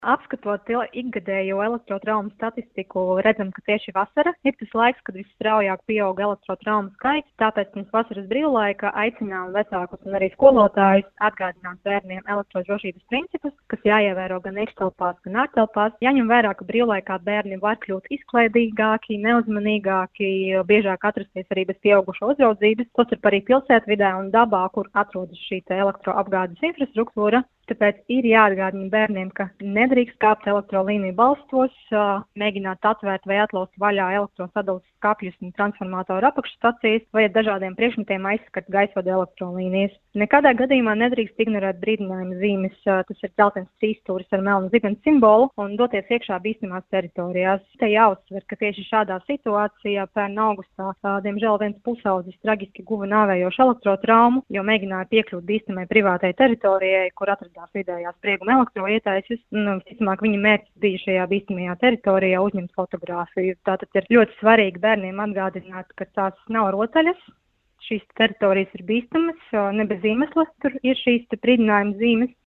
Radio Skonto Ziņās par elektrotraumām vasarā